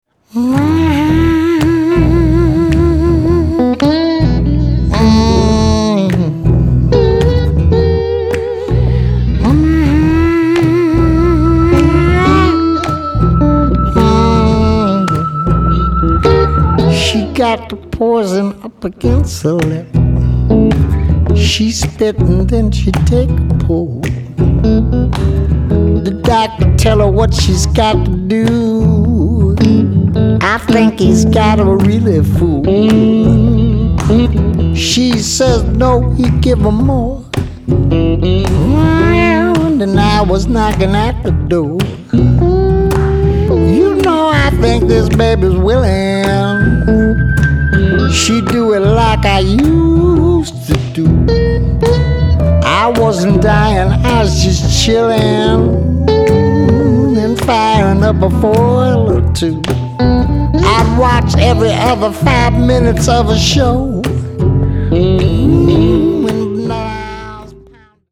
bass, hand claps, vocals
percussion